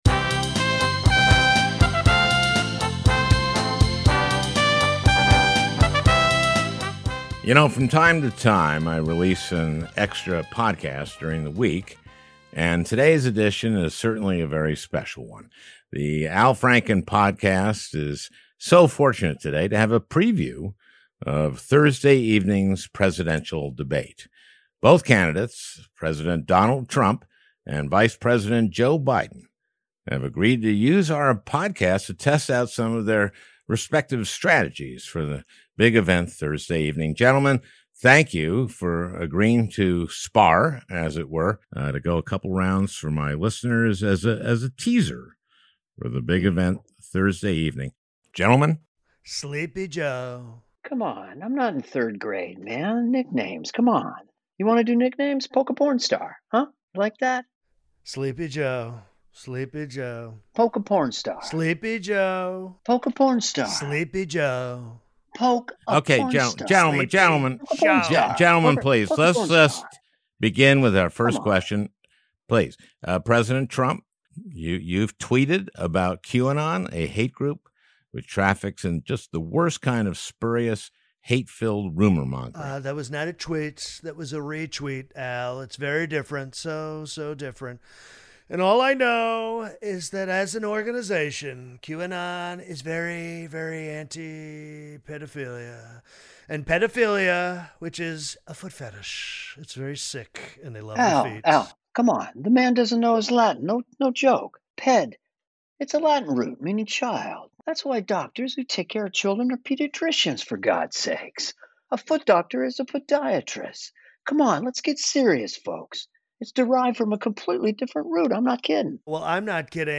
President Donald Trump (Anthony Atamanuik) and Vice President Joe Biden (Dana Carvey) face off in a preview of the final debate of 2020.